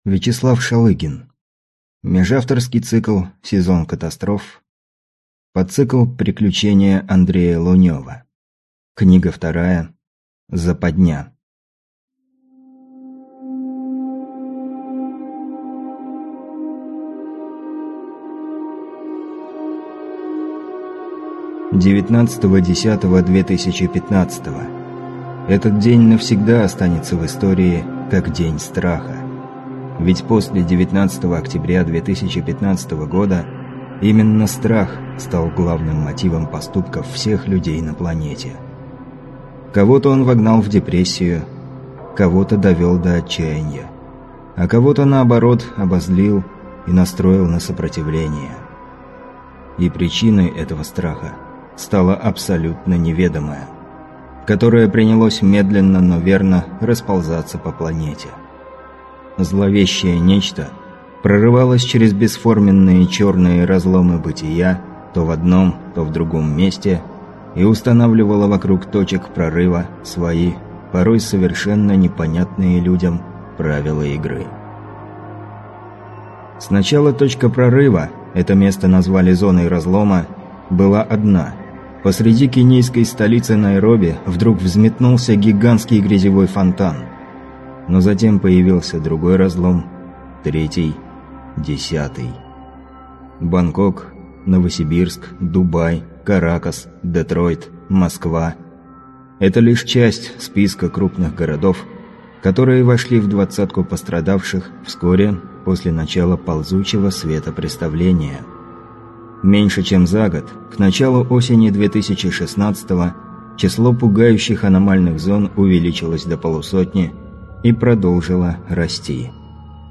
Аудиокнига Zападня | Библиотека аудиокниг